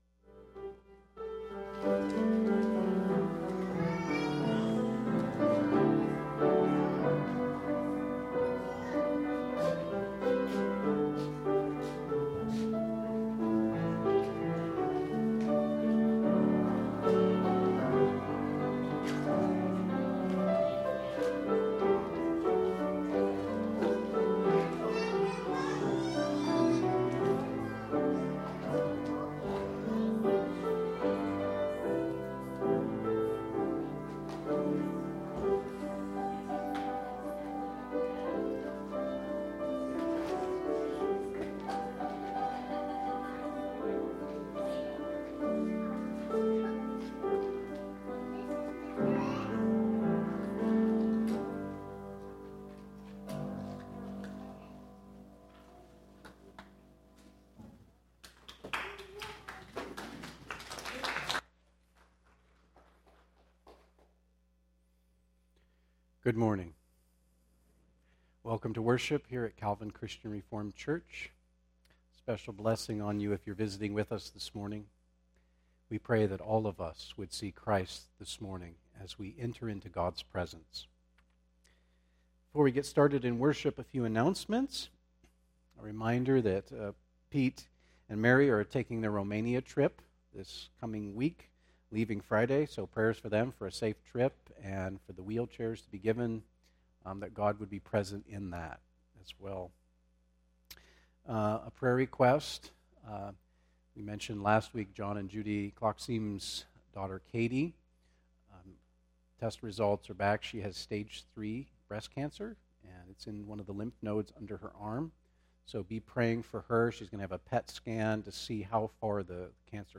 Calvin Christian Reformed Church Sermons